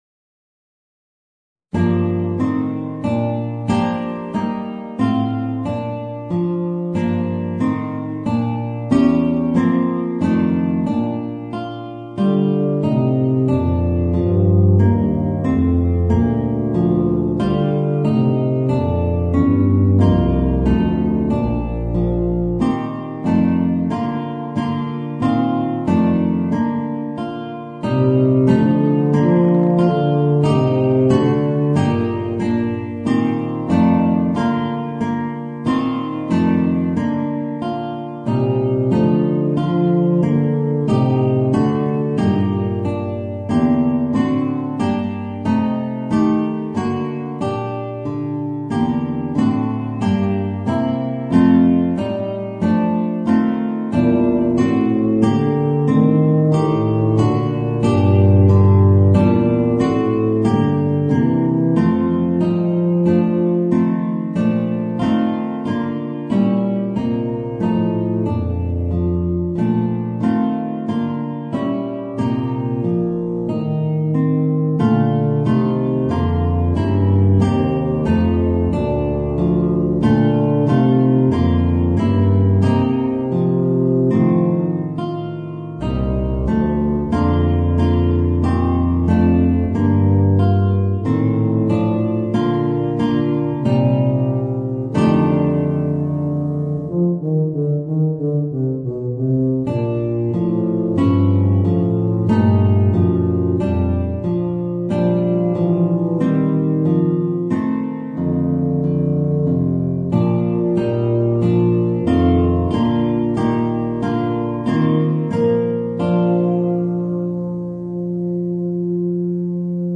Voicing: Guitar and Bb Bass